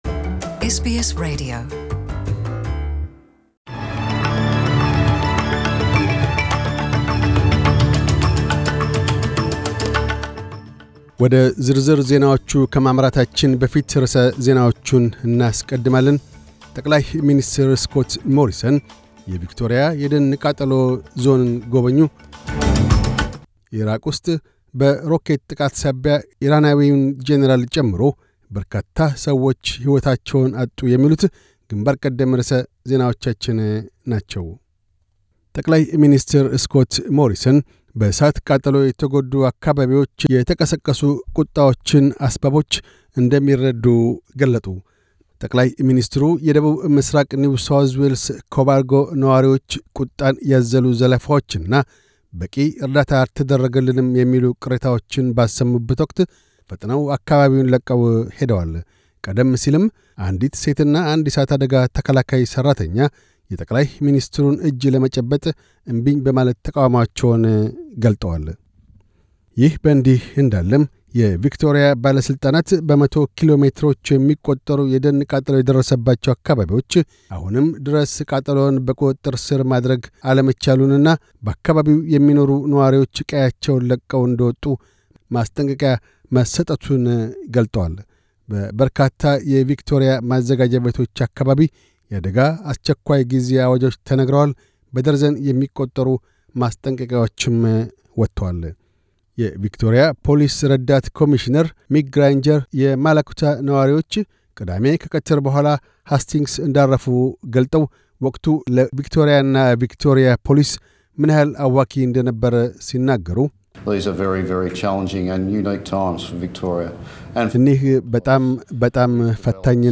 News Bulletin 0301